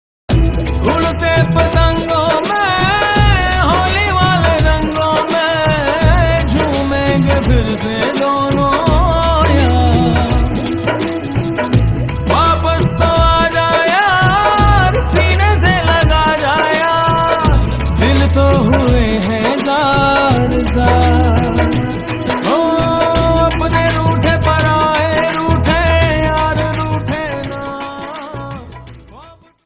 Sad Holi song Ringtone